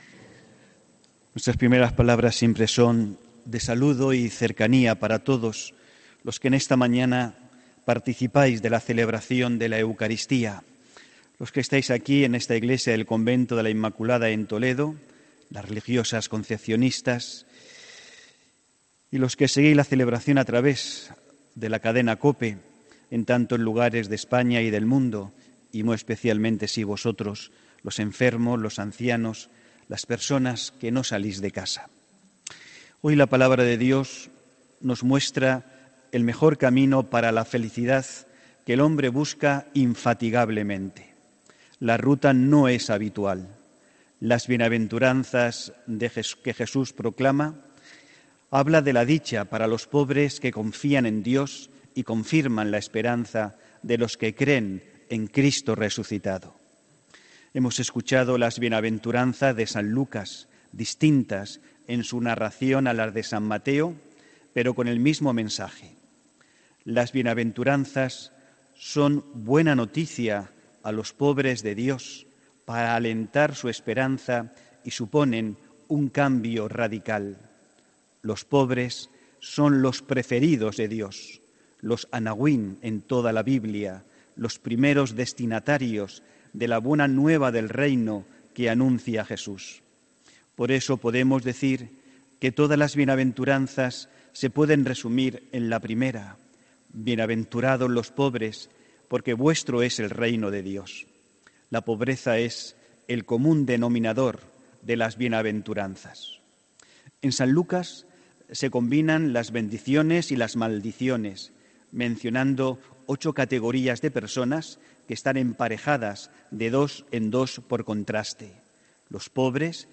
HOMILÍA 17 FEBRERO 2019